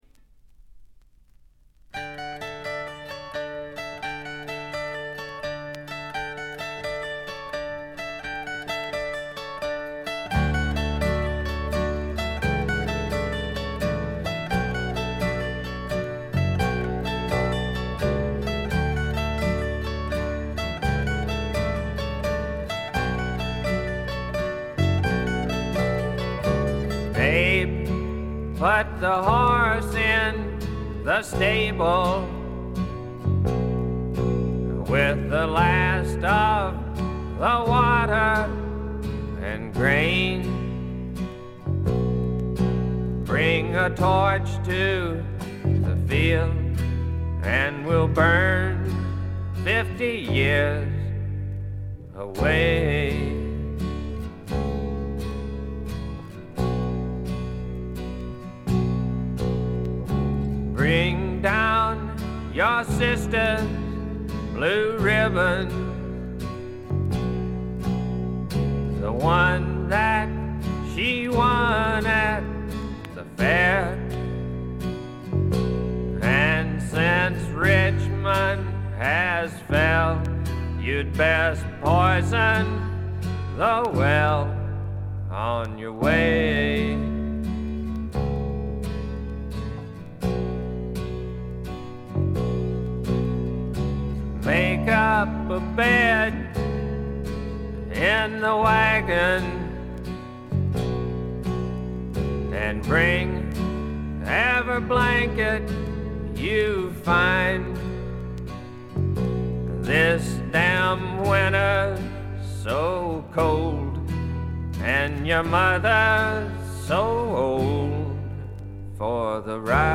静音部で軽微なチリプチ。
いかにもテキサス／ダラス録音らしいカントリー系のシンガー・ソングライター作品快作です。
ヴォーカルはコクがあって味わい深いもので、ハマる人も多いと思います。
試聴曲は現品からの取り込み音源です。